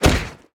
Minecraft Version Minecraft Version latest Latest Release | Latest Snapshot latest / assets / minecraft / sounds / entity / player / attack / knockback1.ogg Compare With Compare With Latest Release | Latest Snapshot
knockback1.ogg